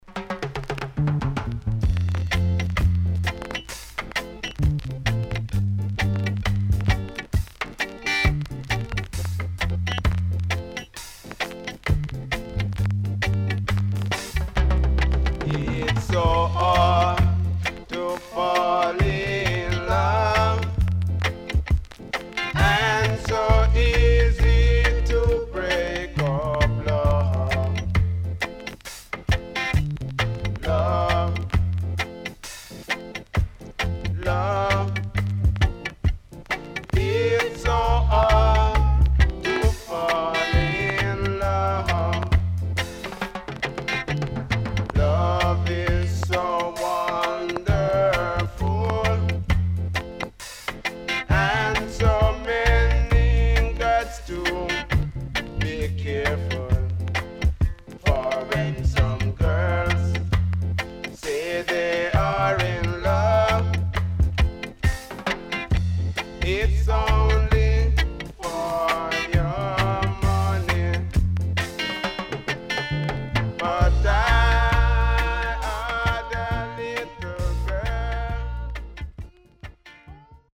W-Side Good Vocal
SIDE A:少しノイズ入りますが良好です。